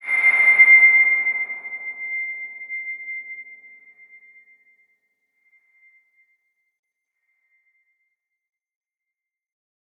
X_BasicBells-C5-pp.wav